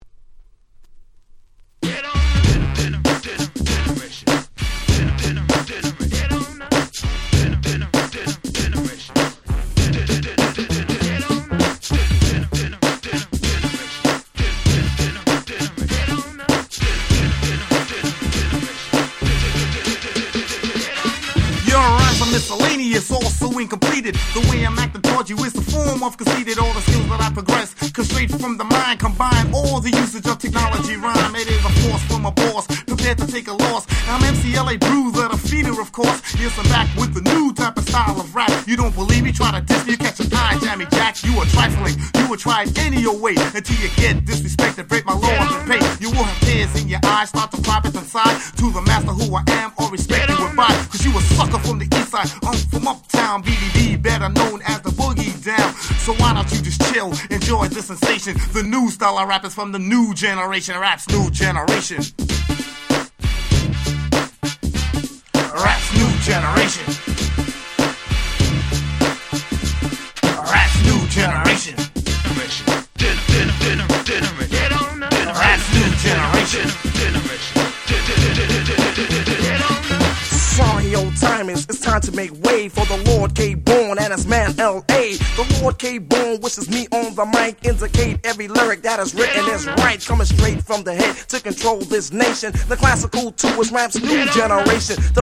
87' Middle School / Old School Hip Hop Classics !!
「Old Schoolの延長」と言うよりは「これから始まる90's Hip Hopの原型」と言った感じでしょうか。